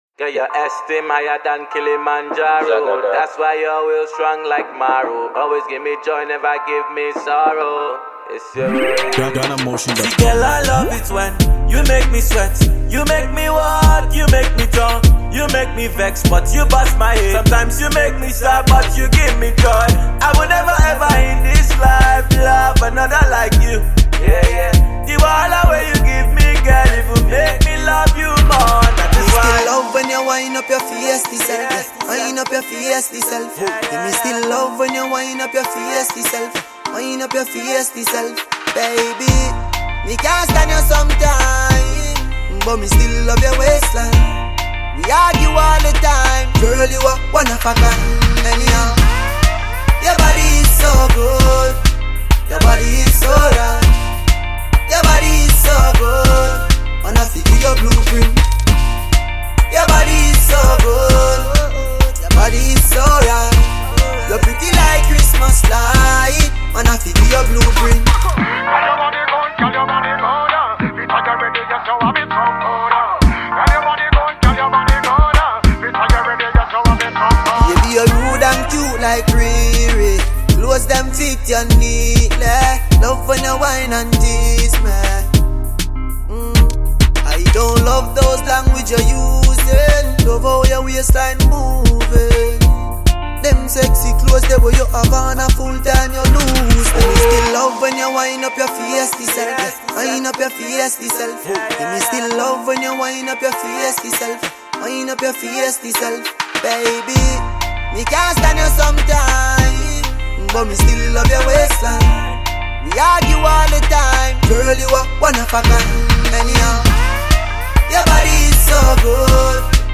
Jamaican dancehall
Afro-pop
a unique fusion of African vibes and Caribbean energy